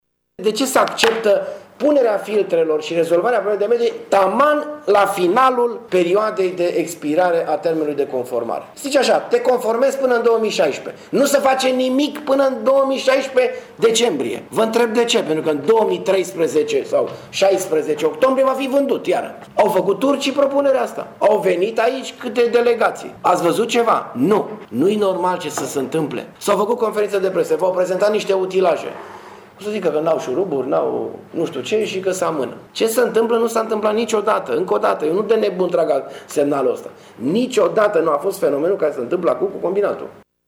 Așa a declarat azi, într-o conferință de presă, primarul municipiului Tîrgu-Mures, Dorin Florea.